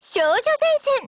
SPAS15_TITLECALL_JP.wav